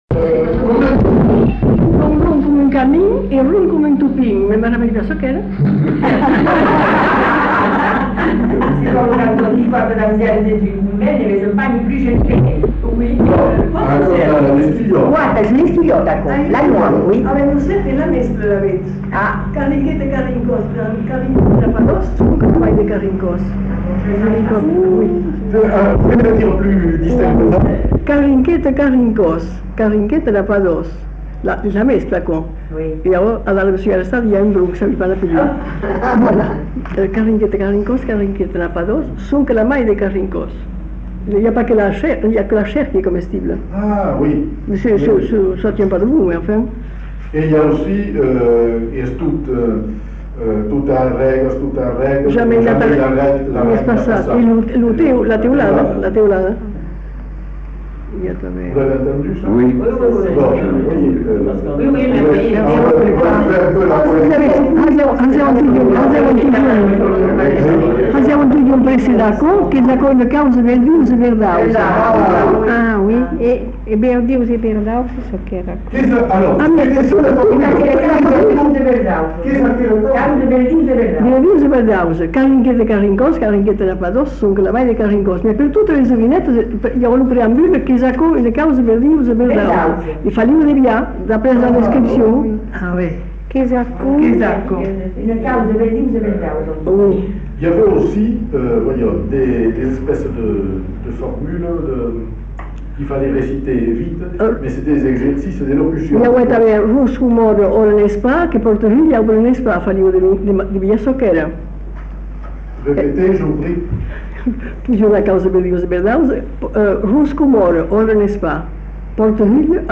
Devinettes
Aire culturelle : Bazadais
Lieu : Bazas
Type de voix : voix de femme
Production du son : récité
Classification : devinette-énigme